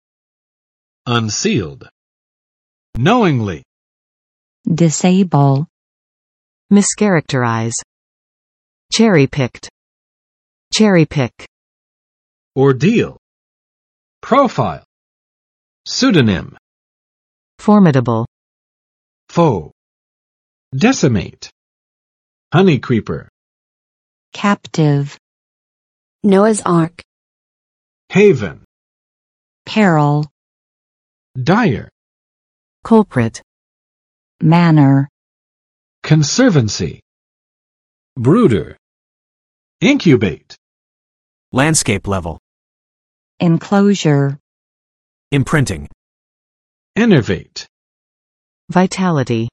[ʌnˋsild] adj. 未证实的